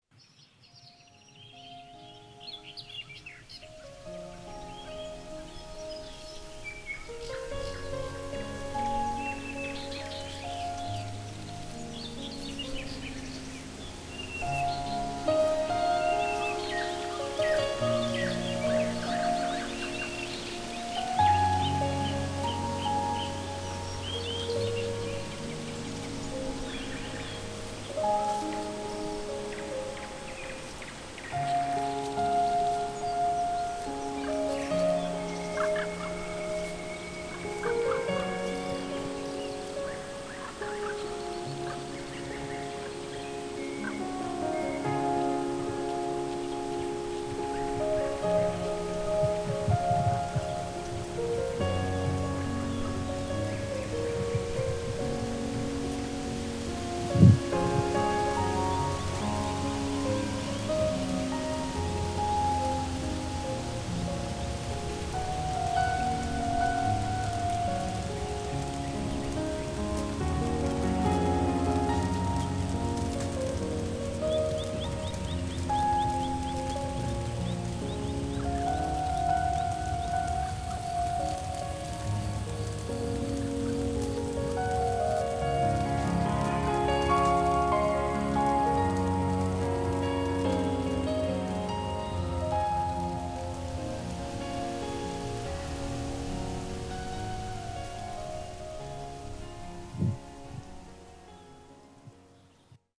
AI-tuned Nature Sounds
birds.mp3